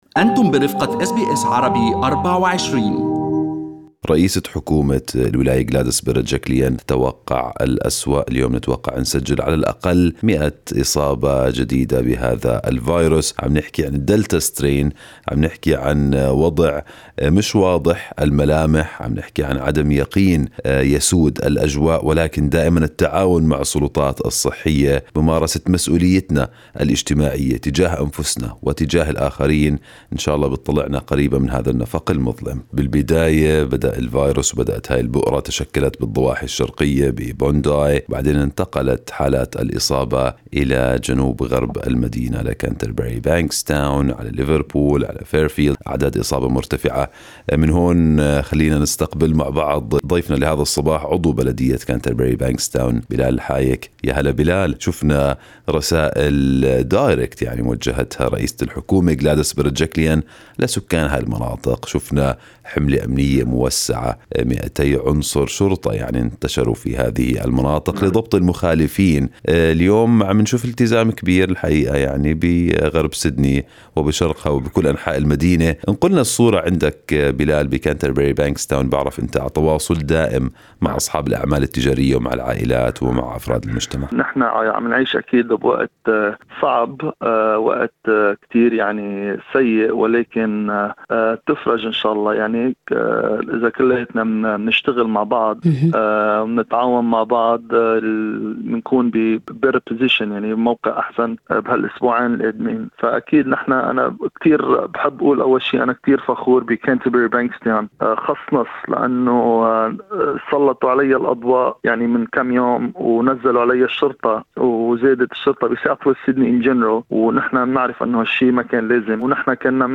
"ملتزمون بالتعليمات": عضو بلدية Canterbury Bankstown يشيد بالتزام سكان المنطقة بالقيود الجديدة